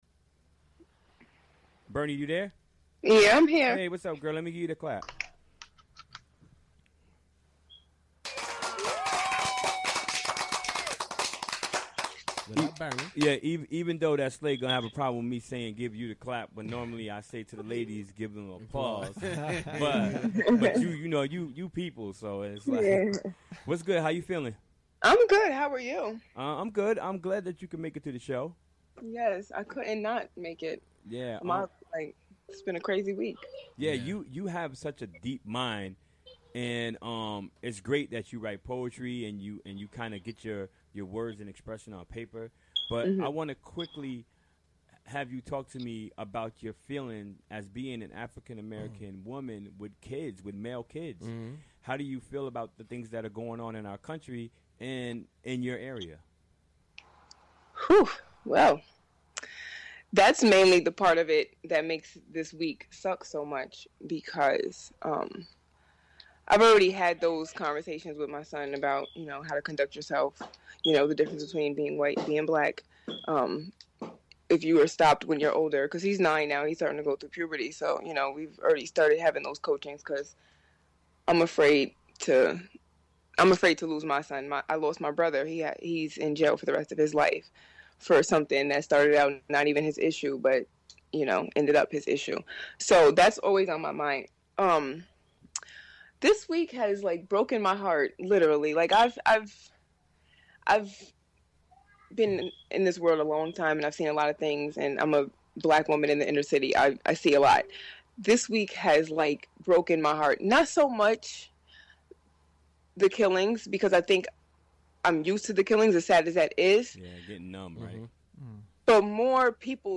Recorded during the WGXC Afternoon Show on Wednesday, July 13, 2016.